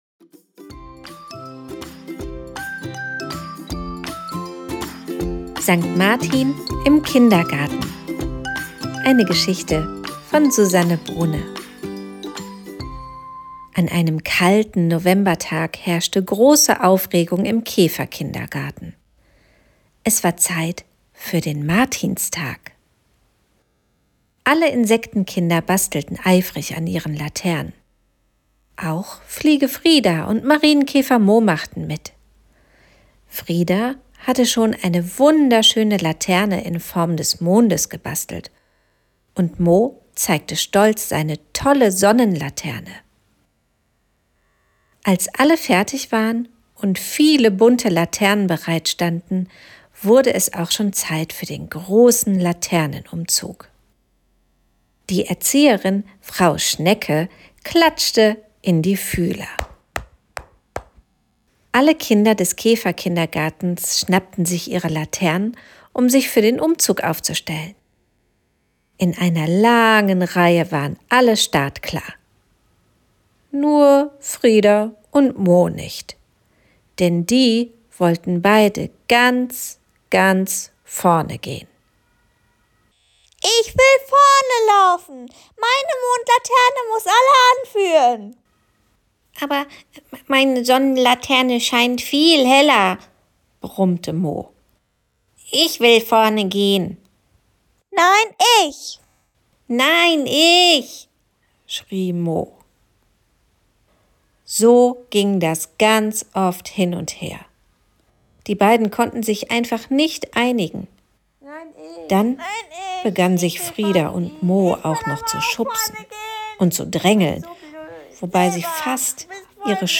Genres: Kids & Family, Stories for Kids
Hörspiel: Der Laternenumzug im Käferkindergarten